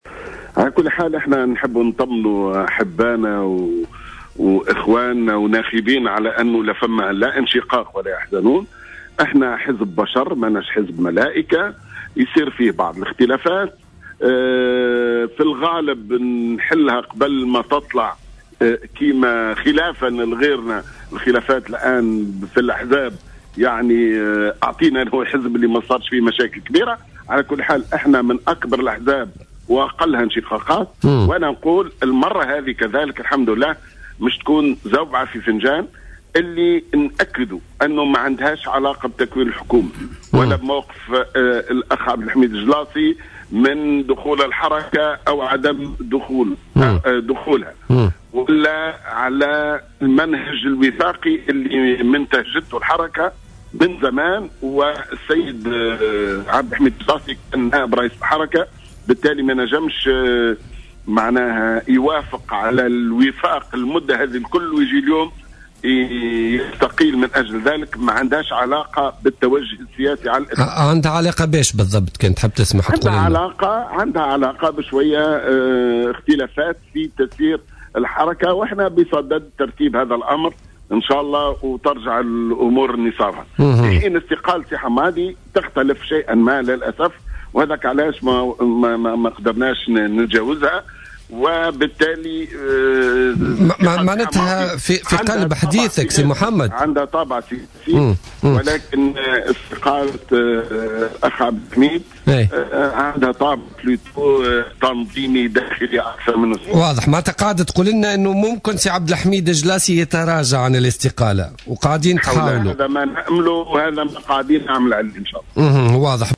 قال القيادي في حركة النهضة،محمد بن سالم في مداخلة له اليوم في برنامج "بوليتيكا" اليوم إن الاختلافات الحاصلة داخل حركة النهضة طبيعية.